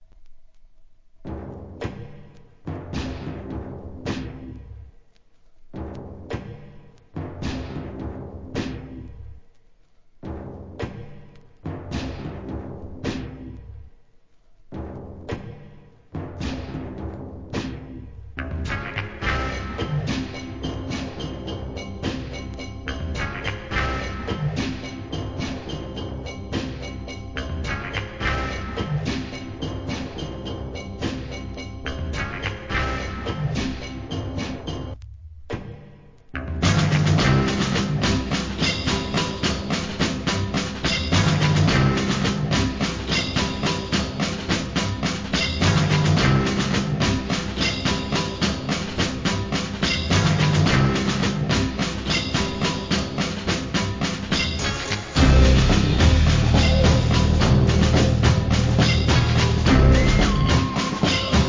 HIP HOP/R&B
JAZZを基調としたブレイクビーツ!!